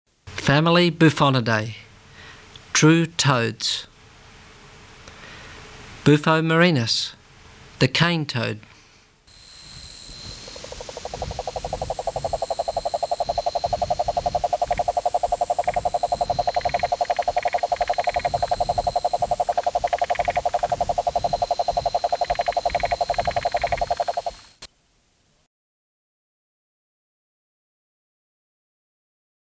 Marine Toad, South American Cane Toad, Cane Toad, Giant Toad  family: Bufonidae
Country Australia
Rhinella_marina.mp3